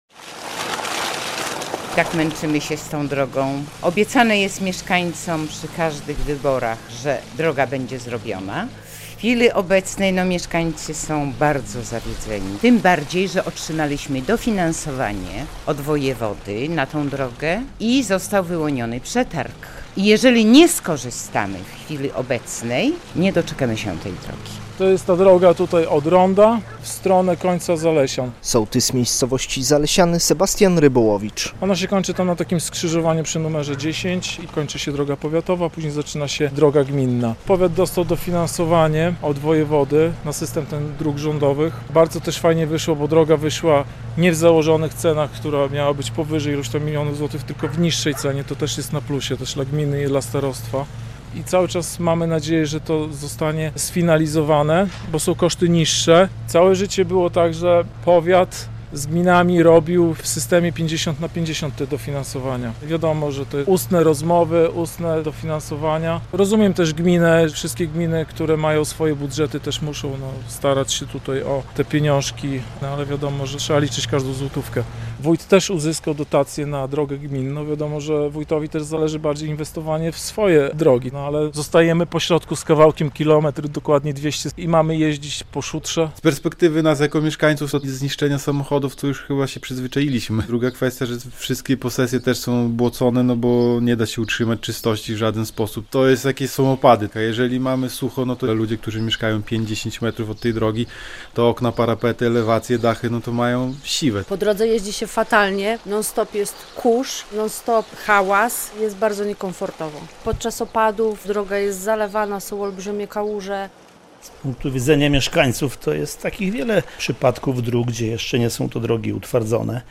Mieszkańcy Zalesian apelują o przebudowę drogi - relacja